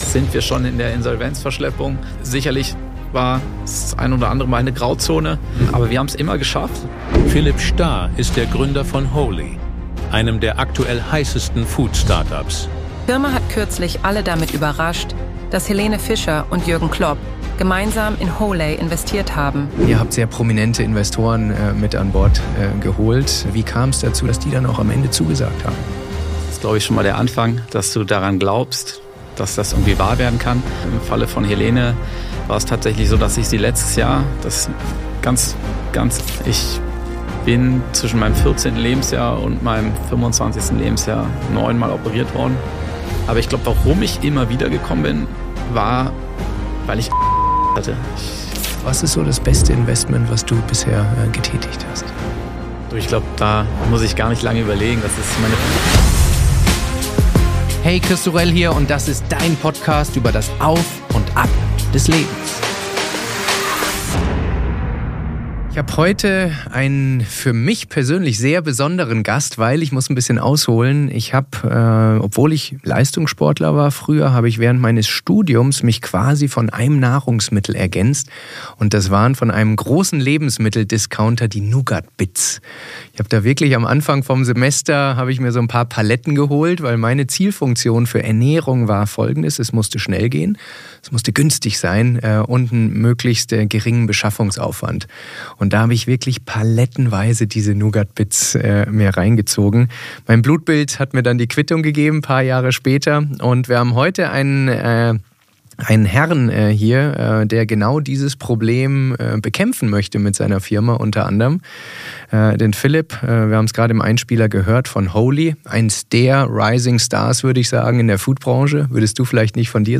Ein Gespräch über Insolvenzverschleppung, mentale Stärke, unrealistische Träume und wie es sich anfühlt, wenn sie wahr werden...